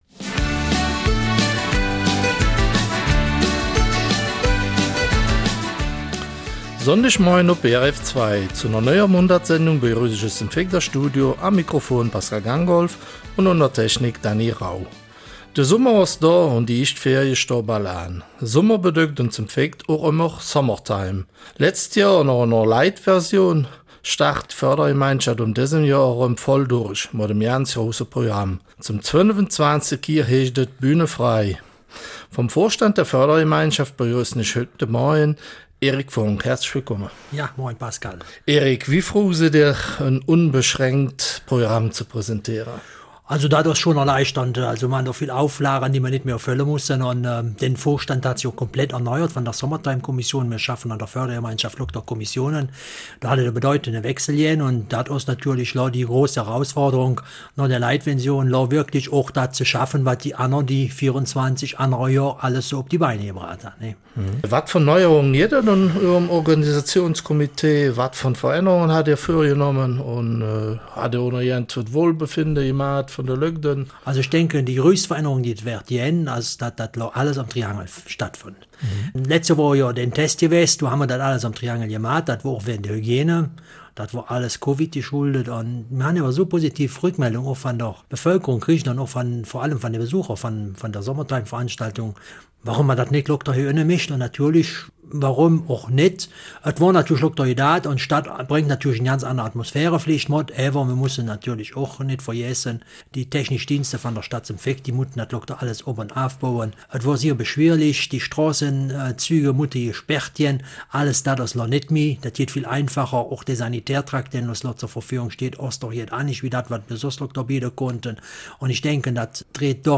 Eifeler Mundart: Summertime 2022 in St.Vith